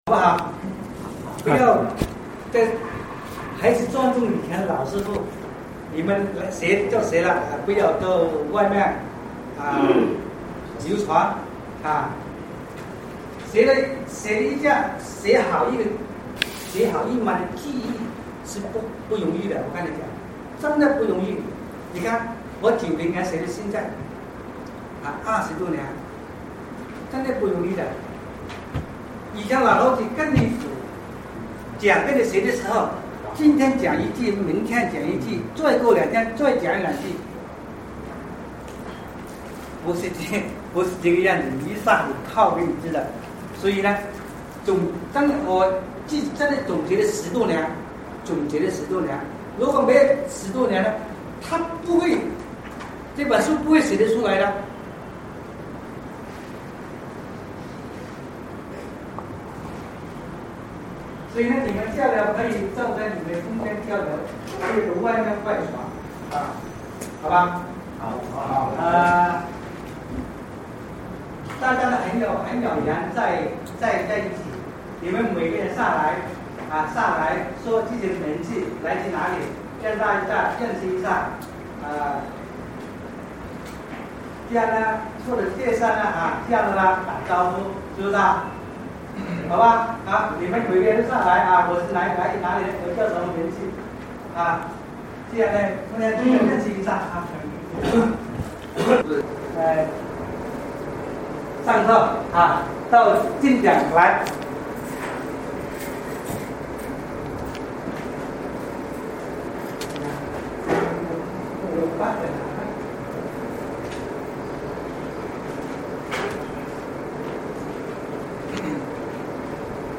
民间天心正运择日风水10月面授班录音
民间天心正运择日风水7月面授班录音